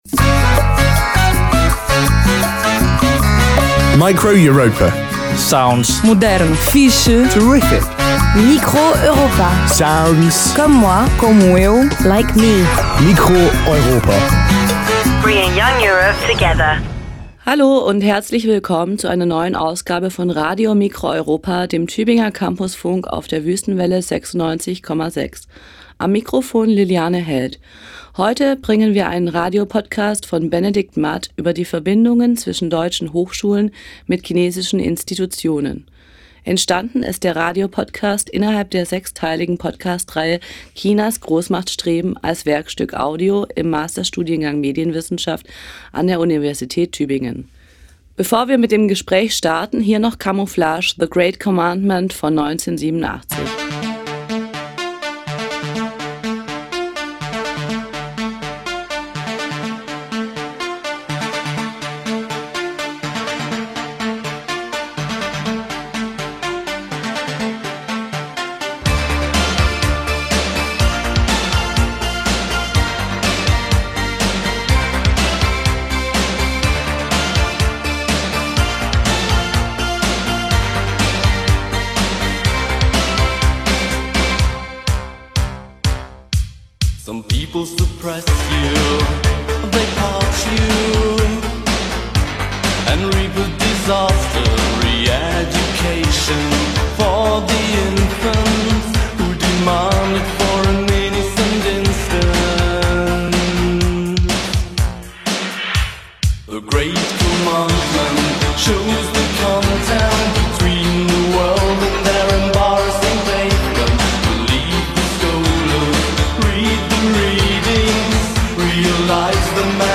Form: Live-Aufzeichnung, geschnitten